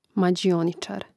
mađiòničār mađioničar